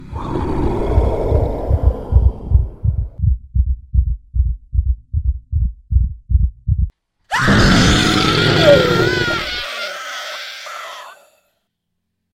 Звуки ужаса или для создания эффекта чего-то ужасного для монтажа видео
8. Звук, где человек испытывает ужас